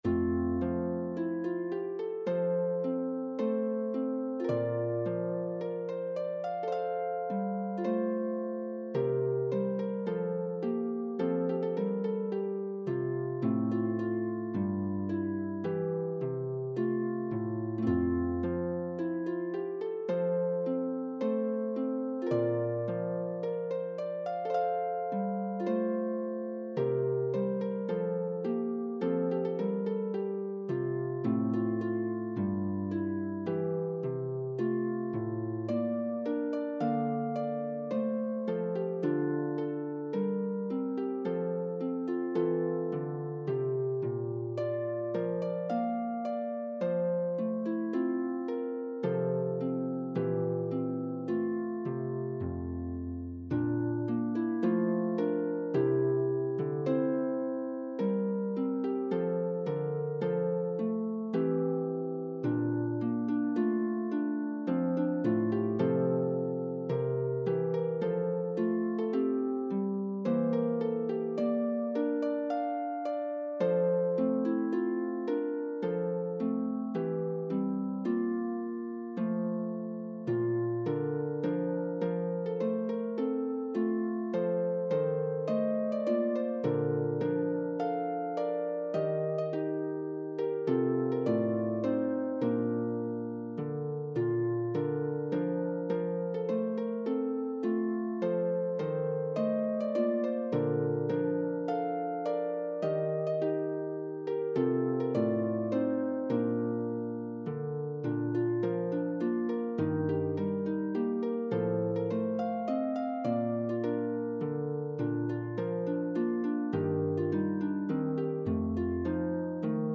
1. Polonaise in the key of F
2. Minuet in the key of B flat
3. Air in F